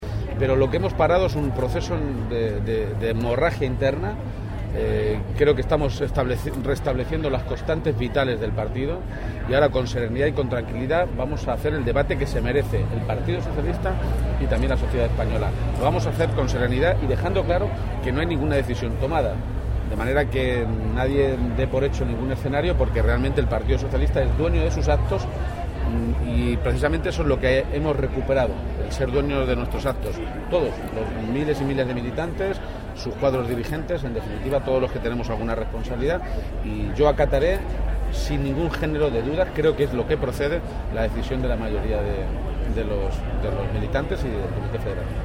García-Page, que ha realizado estas manifestaciones antes de la inauguración de la Feria de Artesanía de Castilla-La Mancha (Farcama), ha añadido que el PSOE “ha abandonado la histeria” para iniciar ese debate “en el que tenemos muy claro que lo primero es el interés de los españoles porque siempre que le ha ido bien a España le ha ido bien a nuestro partido”.
Cortes de audio de la rueda de prensa